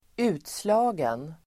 Uttal: [²'u:tsla:gen]